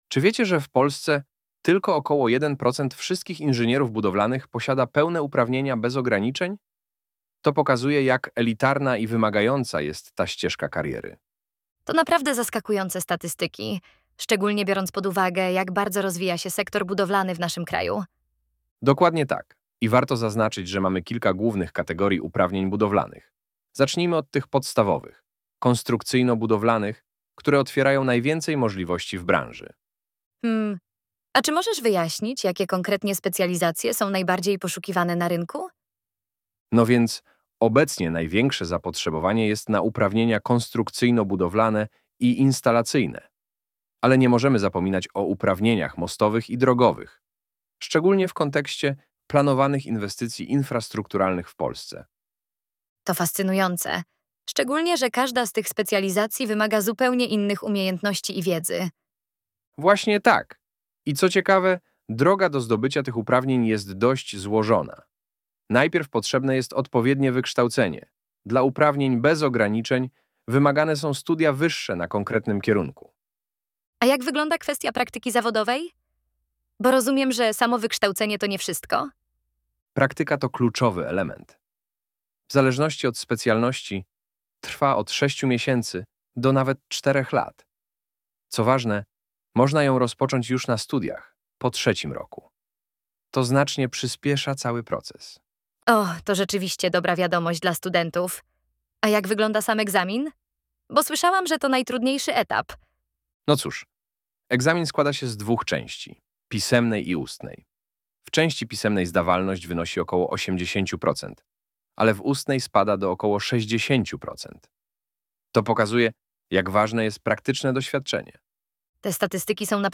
Poznajcie naszych wirtualnych inżynierów Chrisa i Jessicę , którzy opowiedzą wam wiele ciekawych historii w poniższych podcastach 😃